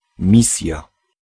Ääntäminen
Synonyymit tâche destination Ääntäminen France: IPA: /mi.sjɔ̃/ Haettu sana löytyi näillä lähdekielillä: ranska Käännös Ääninäyte Substantiivit 1. misja {f} Suku: f .